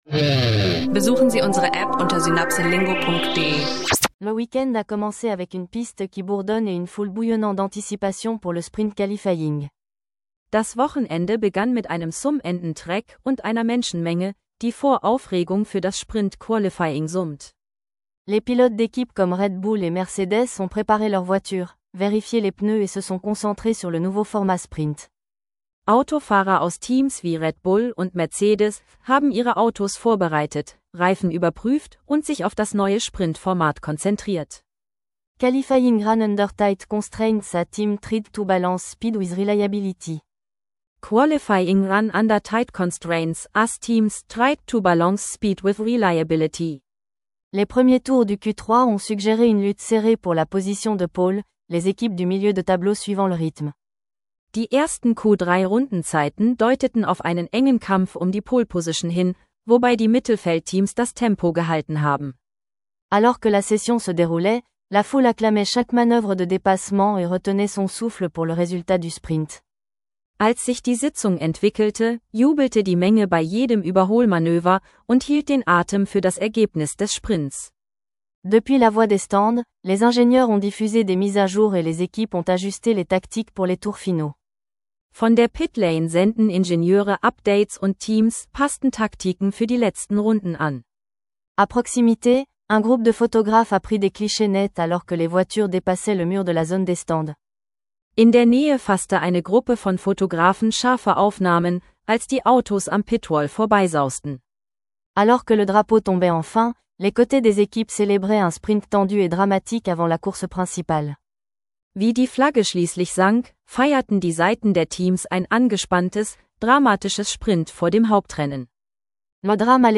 Französisch lernen mit Podcast-Format: Sprint-Qualifying im Fokus – Technik, Vokabeln und Live-Dialoge rund um F1-Drama und Racing